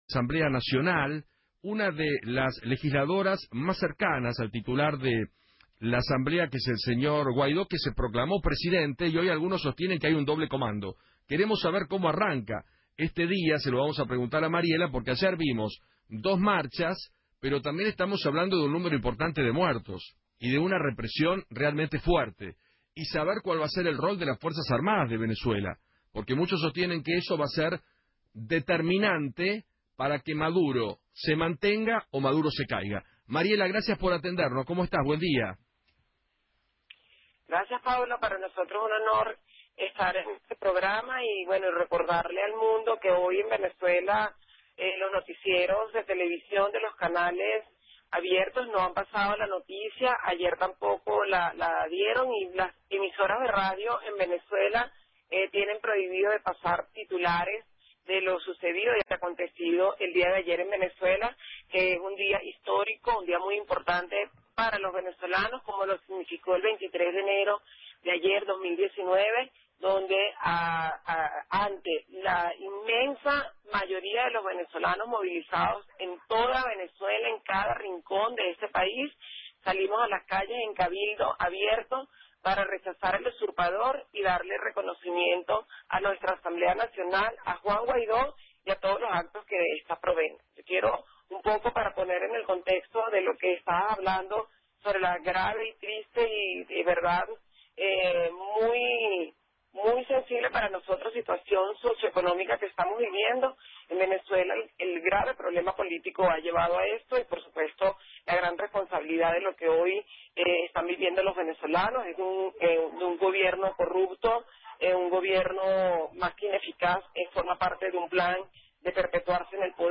Mariela Magallanes, Diputada de Aragua en Venezuela, habló en Feinmann 910 y dijo que “Hoy en Venezuela los canales y las radios no pasaron la noticia de lo ocurrido ayer.